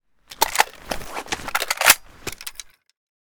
akm_reload.ogg